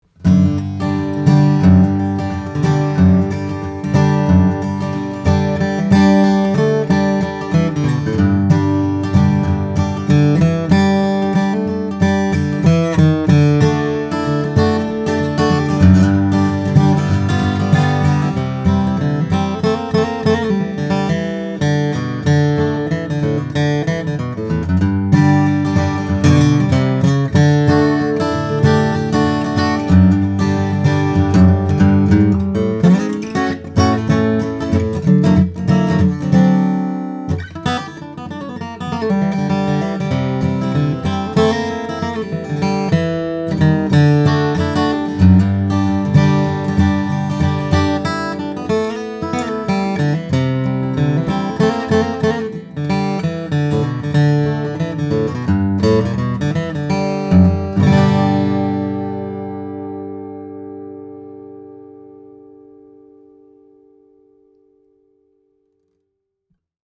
2004 Circa Dreadnought, Cocobolo/Adirondack Spruce - Dream Guitars
The power of this cannon is almost intimidating, and the strong, complex voice sounds like it should come from a guitar seven times…